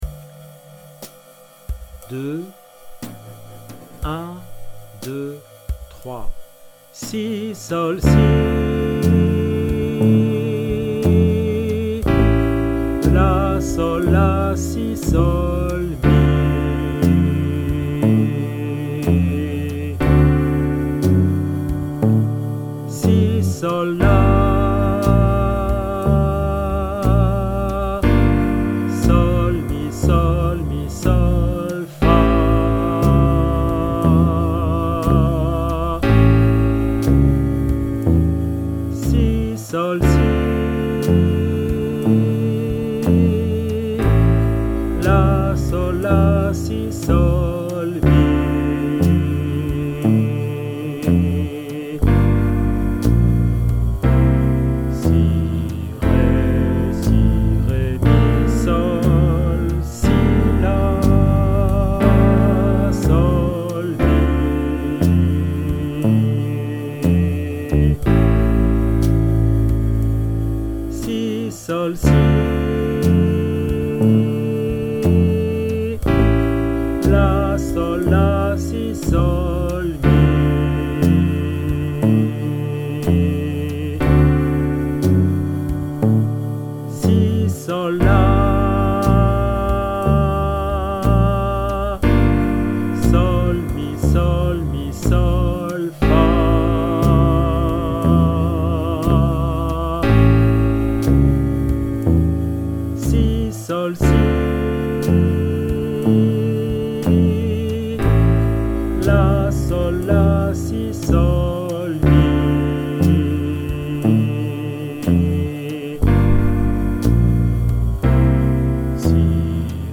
Des playback pour : Summertime : Summertime Alto -